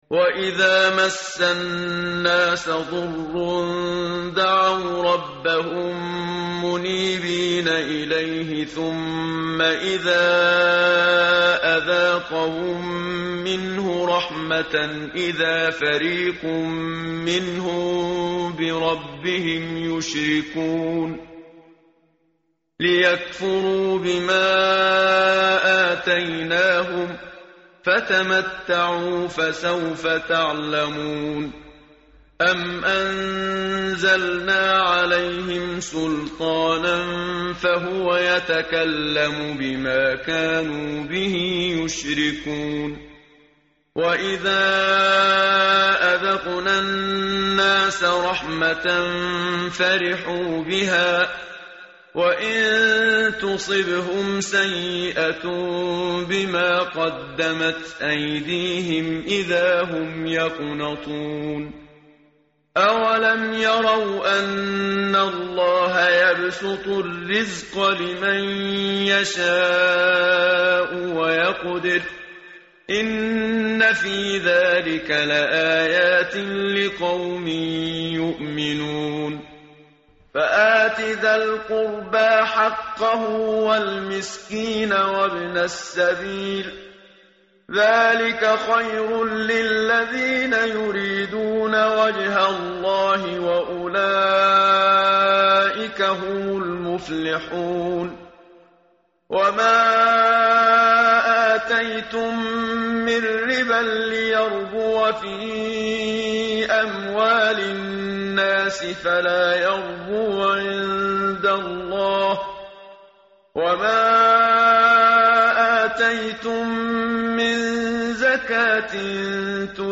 متن قرآن همراه باتلاوت قرآن و ترجمه
tartil_menshavi_page_408.mp3